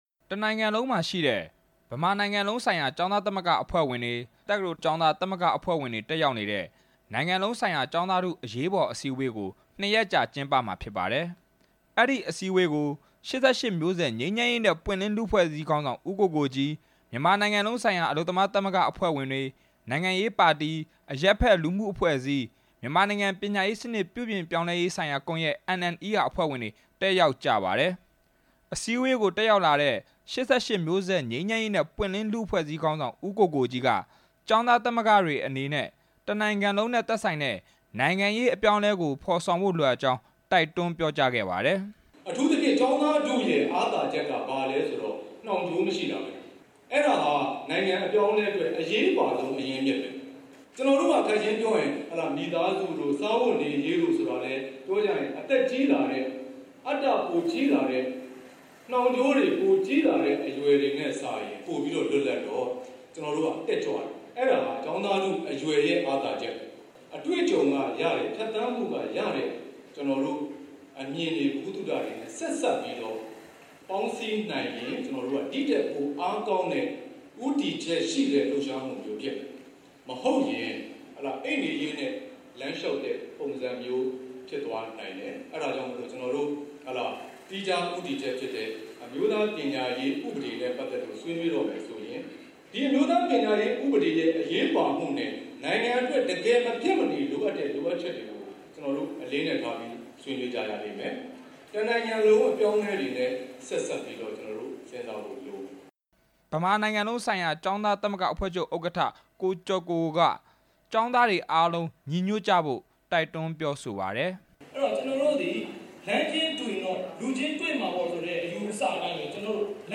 ဆွေးနွေးပွဲအကြောင်း တင်ပြချက်